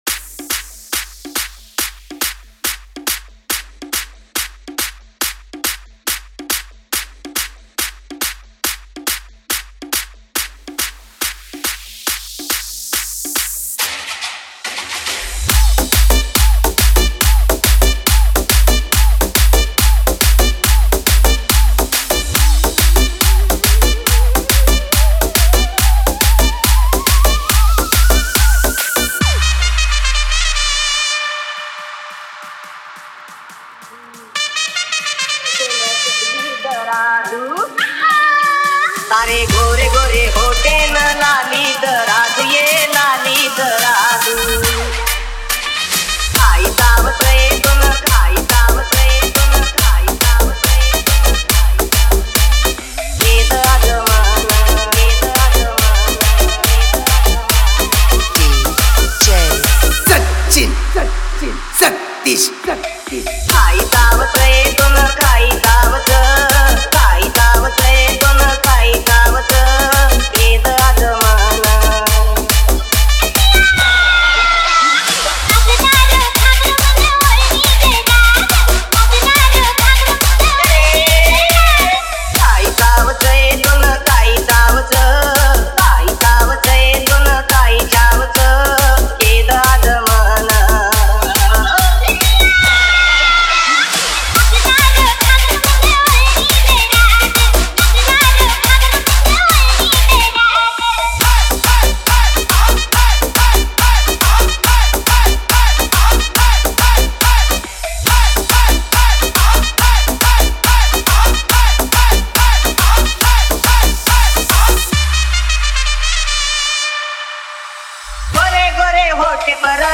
Banjara Dj Songs